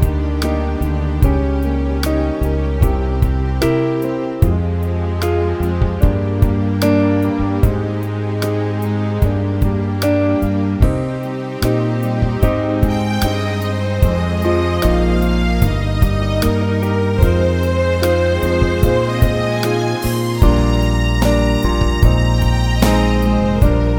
Minus Harmonica Pop (1970s) 3:38 Buy £1.50